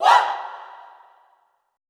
Index of /90_sSampleCDs/Best Service - Extended Classical Choir/Partition I/FEM SHOUTS
FEM OOAH  -R.wav